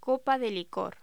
Locución: Copa de licor
voz